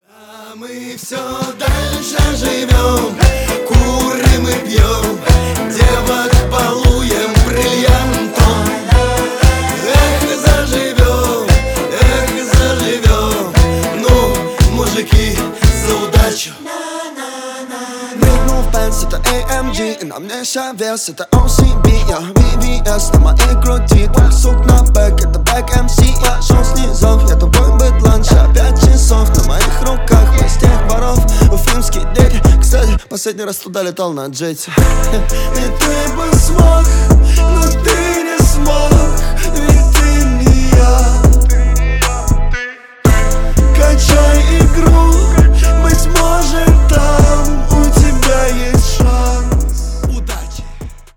• Качество: 320, Stereo
шансон